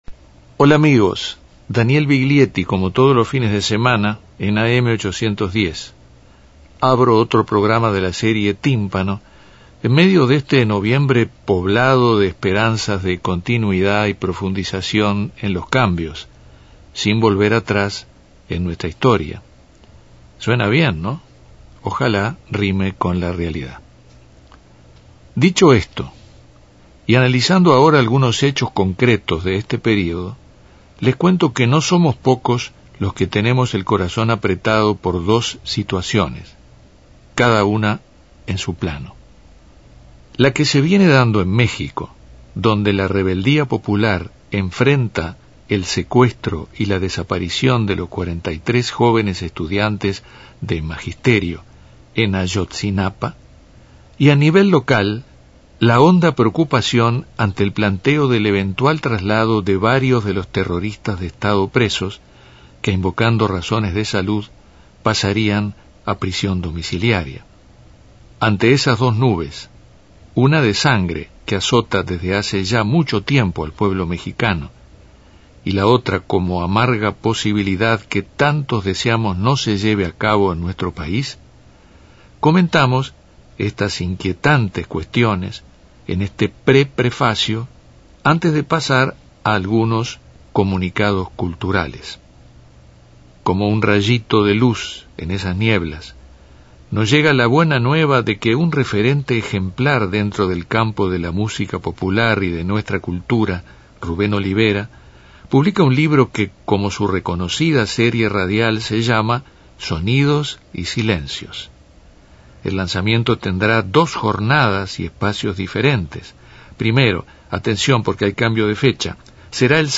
Un adiós, recordando al cantautor argentino desde una entrevista que le hizo Daniel Viglietti en Buenos Aires, rescatada ahora en Tímpano.